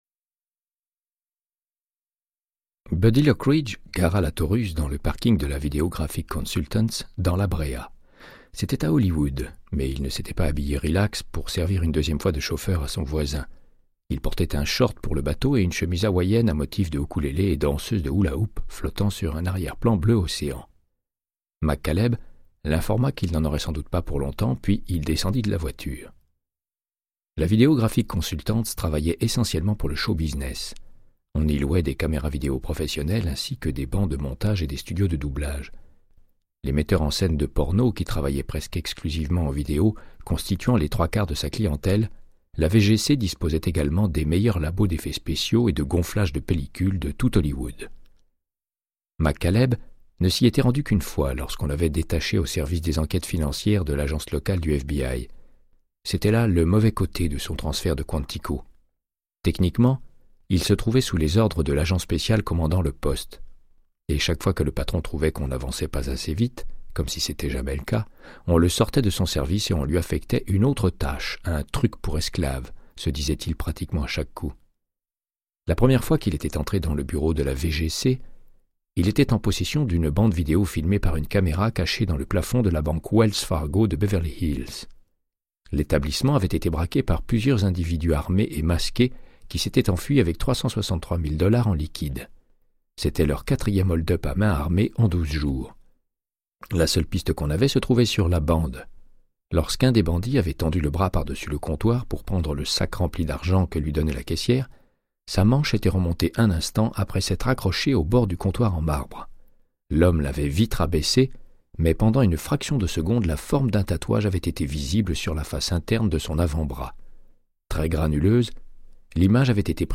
Audiobook = Créance de sang, de Michael Connellly - 56